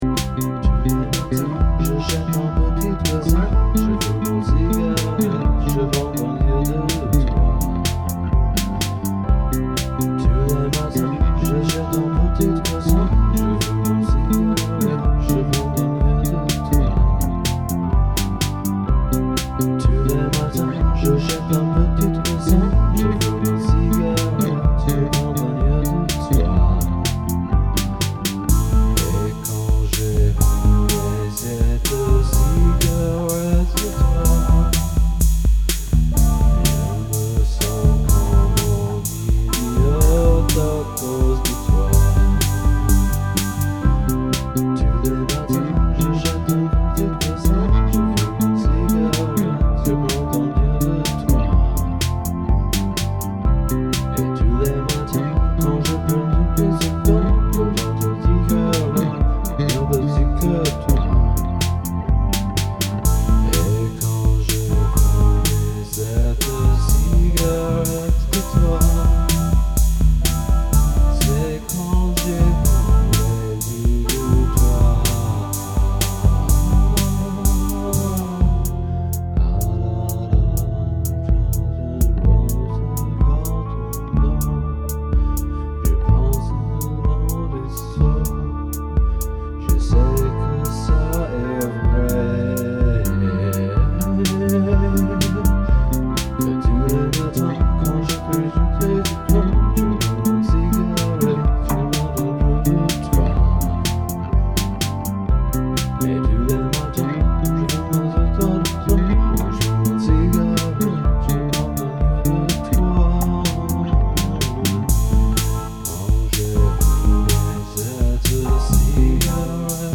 Anyone, this one is the (exact) same vocals with different drums, and an organ (JX3P) instead of the guitar.